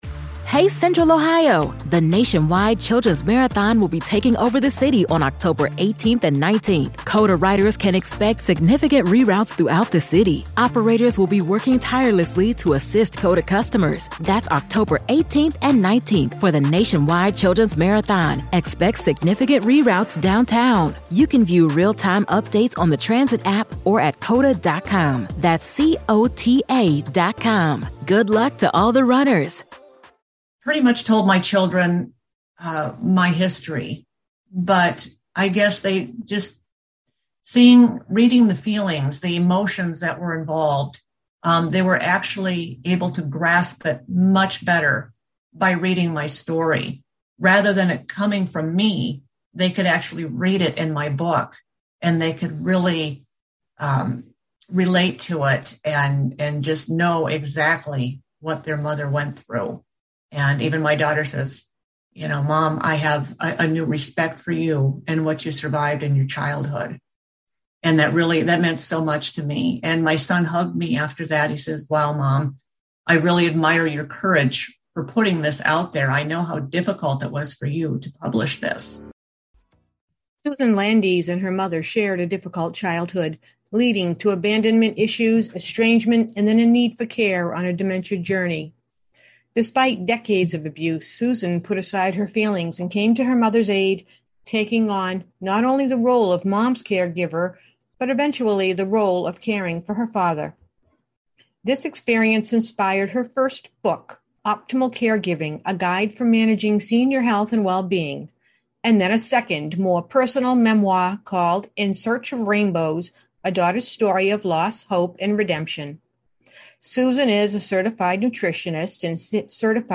In each episode, we interview one of our authors who may have written a memoir, caregiver guide, novel, children’s book, activity guide, poetry book, or blog.